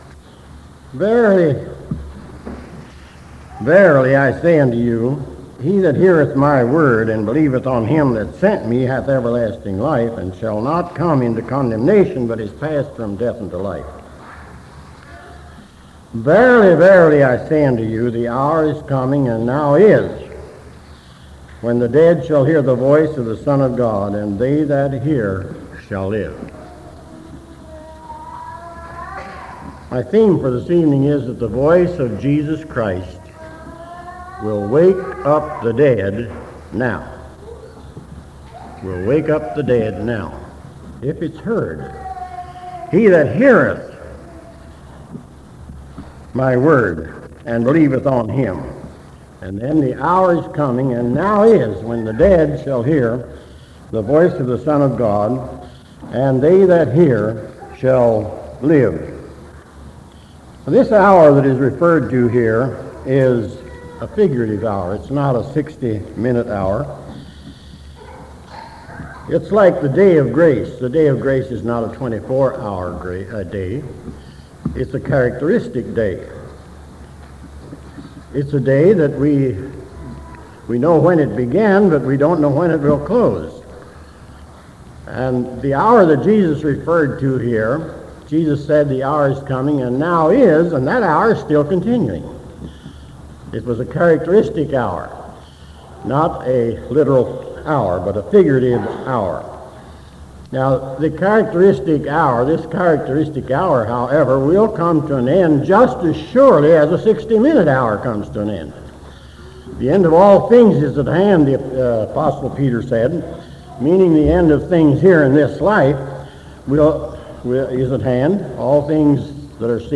Sermons
But if men will hear His voice today, He can raise them spiritually, from death to life. This message was part of a revival series at Shade Mt and has invigorating evangelistic qualities.